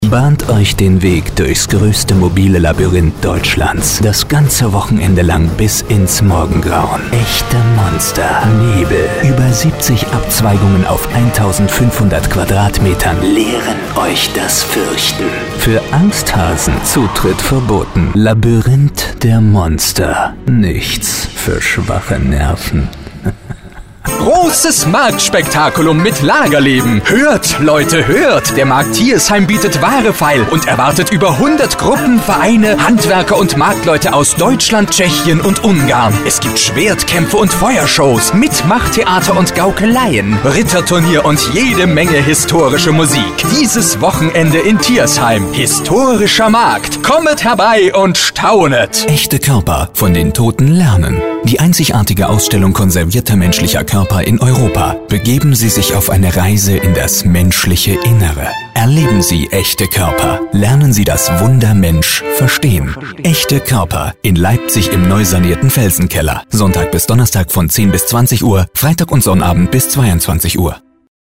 Sprecher englisch (usa) mit einem großen schauspielerischen Spektrum
Sprechprobe: eLearning (Muttersprache):
voice over artist english (us)